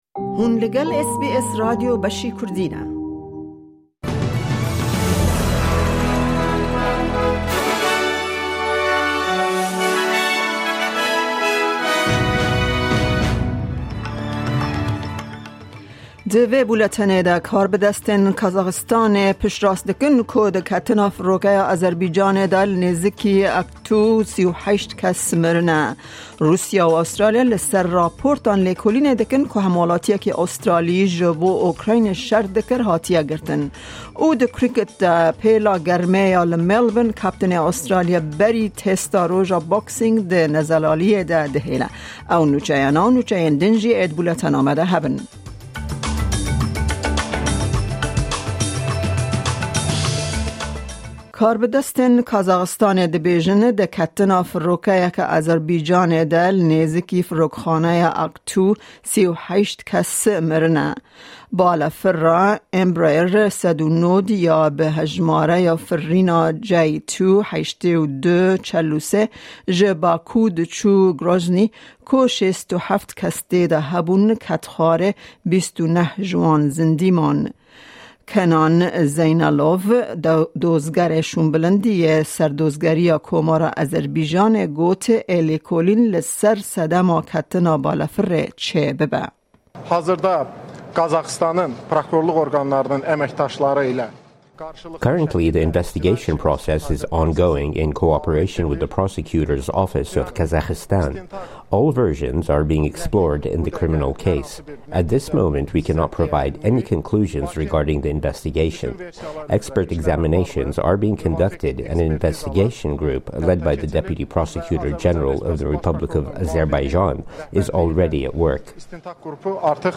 Bûletena nûçeyên roja Pêncşemê 26î Kanûna 2024